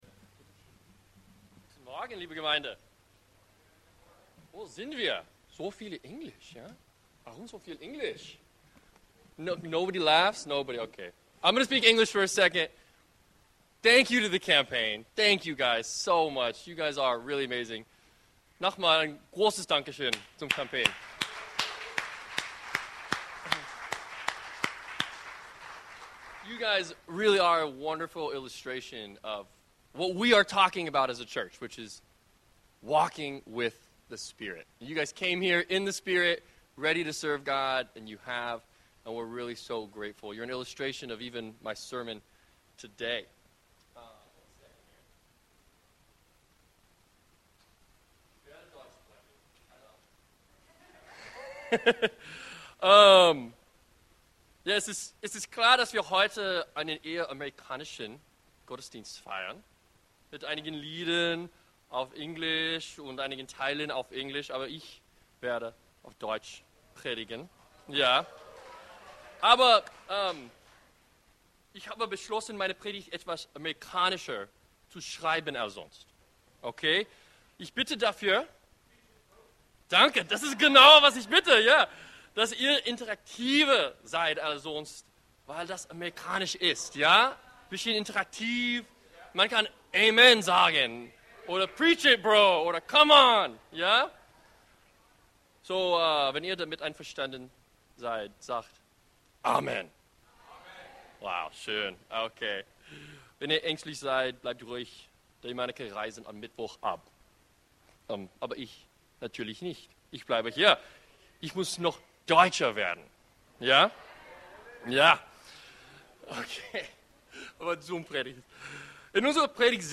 E-Mail Details Predigtserie